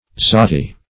Search Result for " zati" : The Collaborative International Dictionary of English v.0.48: Zati \Za"ti\ (z[aum]"t[-e]), n. (Zool.)